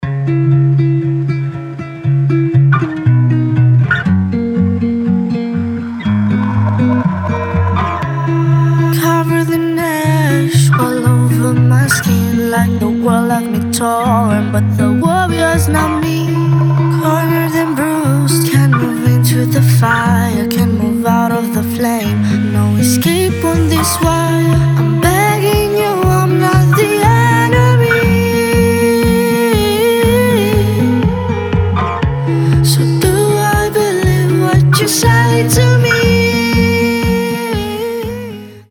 • Качество: 320, Stereo
гитара
приятные
красивый женский голос
теплые
Очаровательный голос!